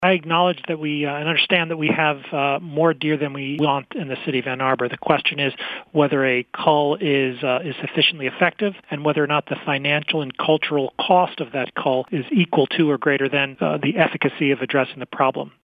Ann Arbor Mayor Christopher Taylor.